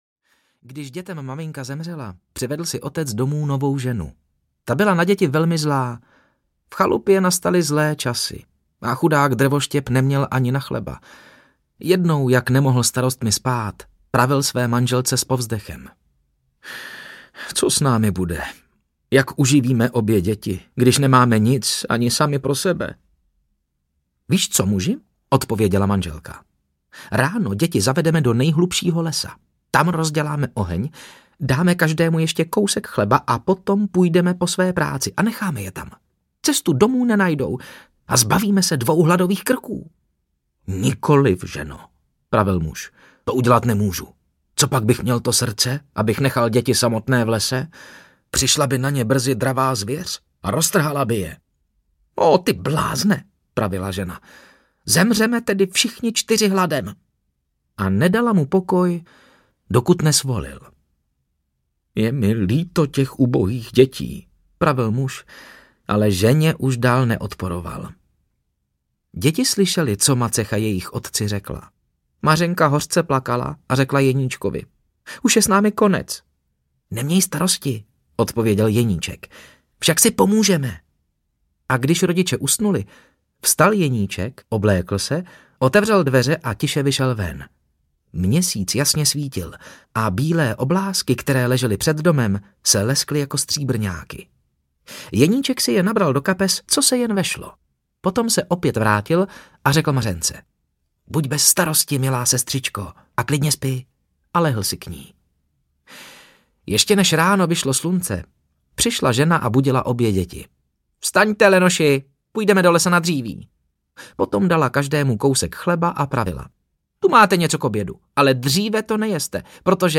Ukázka z knihy
Nové nahrávky, tradiční zpracování, excelentní interpreti, hudební doprovod.